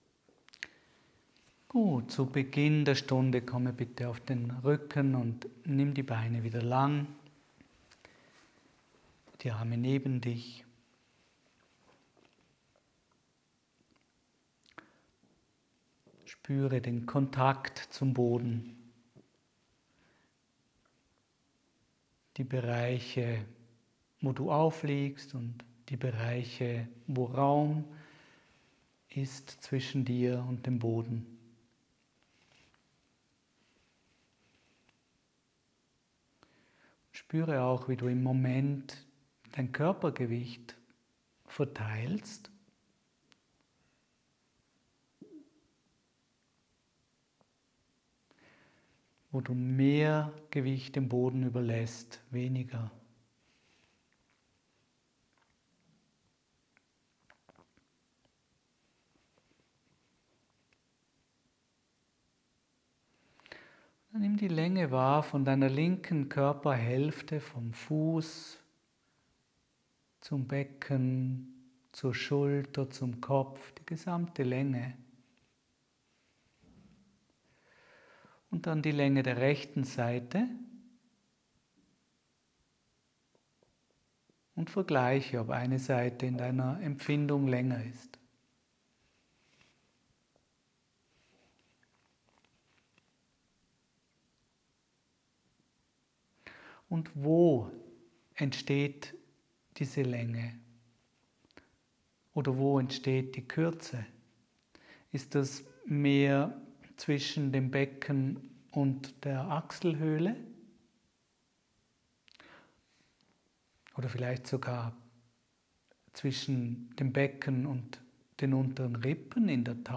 Kostenlose Feldenkrais Audio-Lektion: Beweglichkeit und Stabilität über das Sprunggelenk
Quelle: Ankle Movements AY 251 Die Lektion des Monats ist ein Live-Mitschnitt meiner Gruppenkurse in Feldenkrais ® Bewusstheit durch Bewegung.